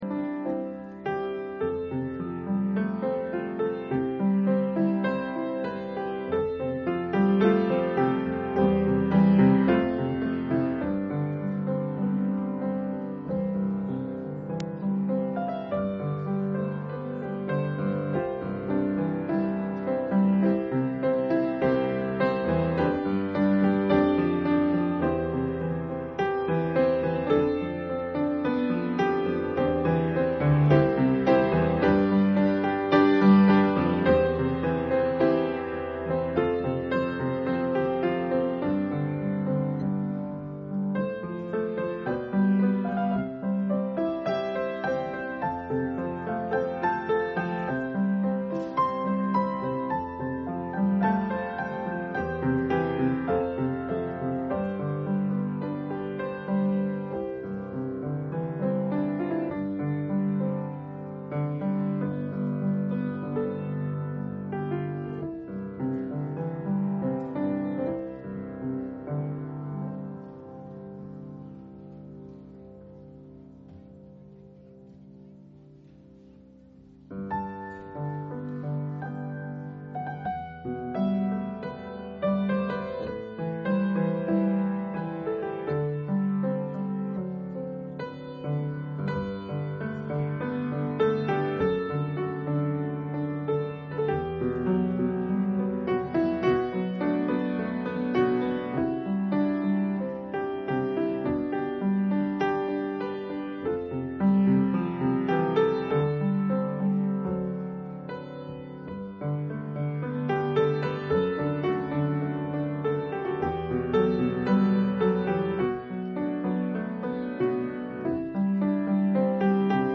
Public Reading of Holy Scripture
Ministry of the Word as summarized in Heidelberg Catechism Lord’s Day 36 RIGHTLY BEARING GOD’S HOLY NAME IS THE DIFFERENCE BETWEEN LIFE AND DEATH!